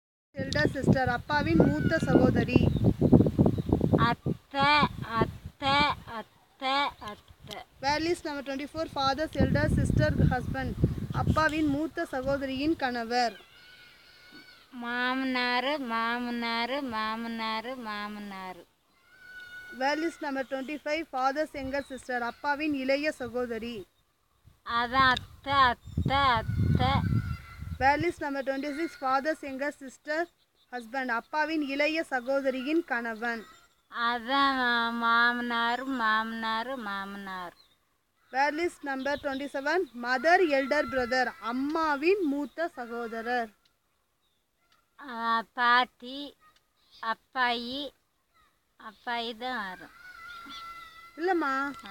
NotesThis is an elicitation of words for kinship terms, using the SPPEL Language Documentation Handbook.